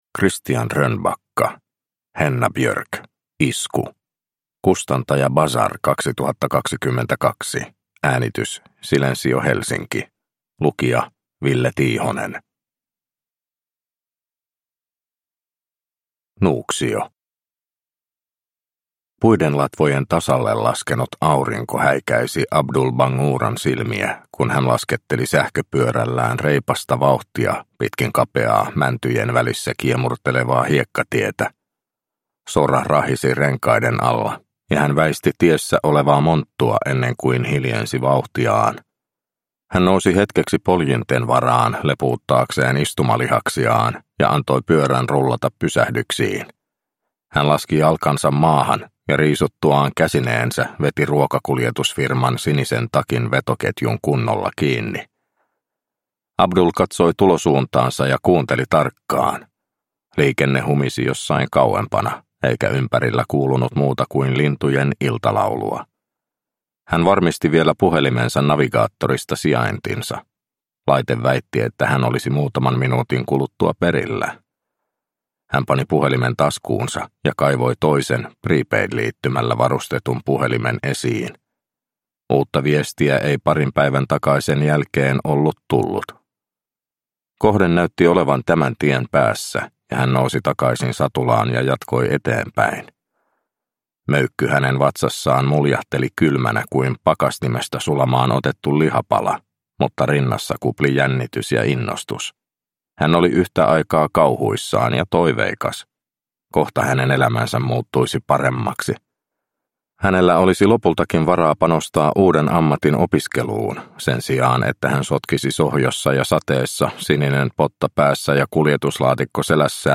Henna Björk: Isku – Ljudbok – Laddas ner